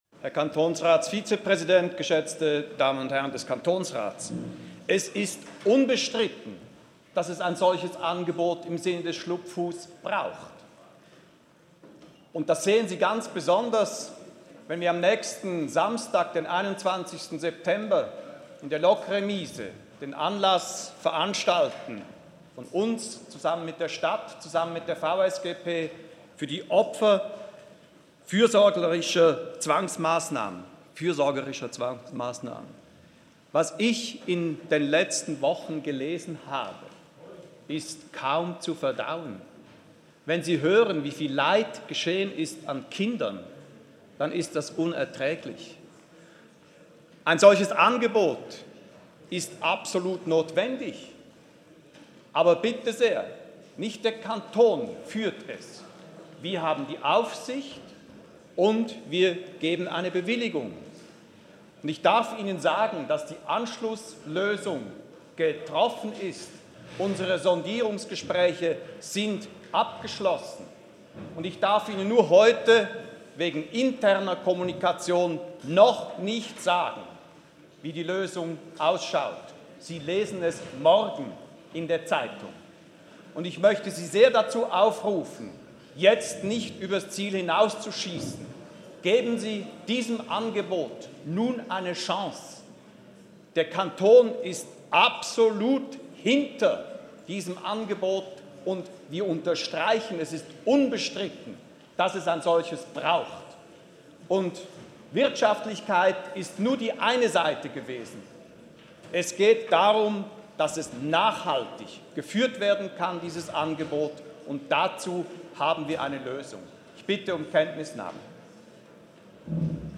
16.9.2019Wortmeldung
Session des Kantonsrates vom 16. bis 18. September 2019